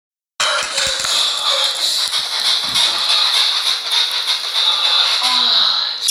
Gemid ohhh
gemid-ohhh.mp3